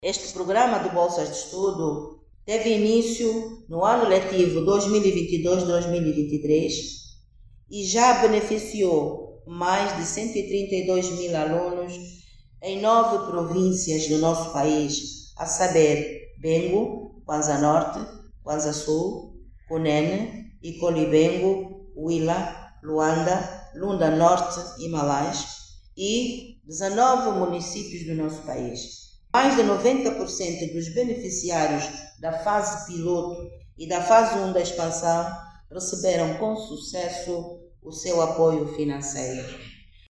A informação foi avançada pela Ministra da Educação, Luísa Grilo durante um encontro de Concertação para a Implementação da Segunda Fase do Programa de Bolsas de Estudo.